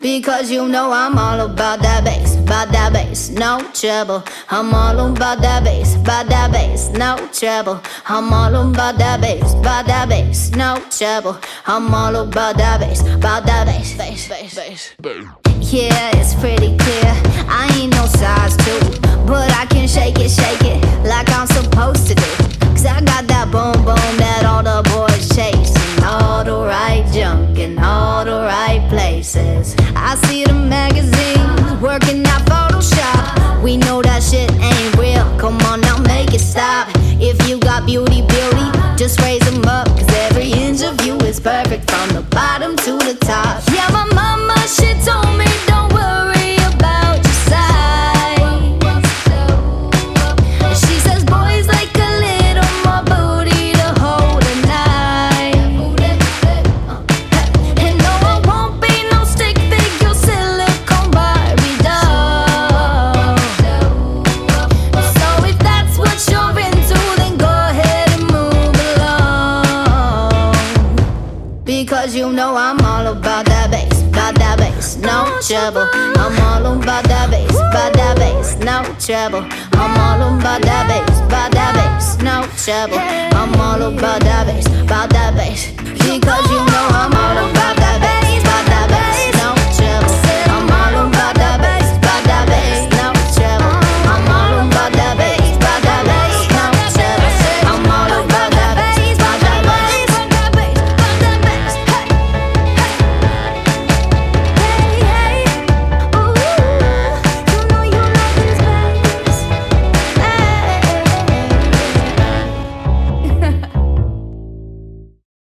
BPM134
MP3 QualityMusic Cut